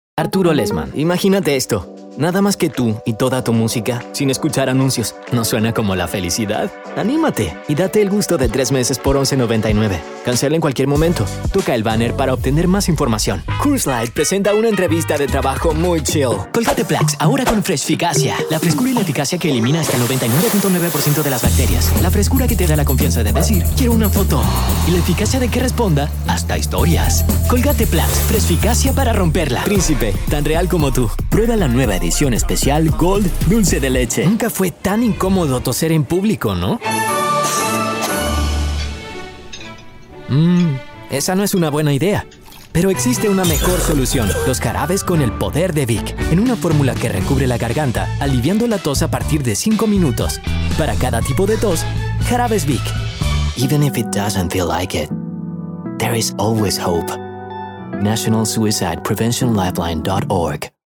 Locutor panameño con una voz fresca y juvenil con acento neutro latinoamericano.
Tengo un estudio con calidad broadcast para grabaciones inmediatas equipado con:
• Neumann TLM-103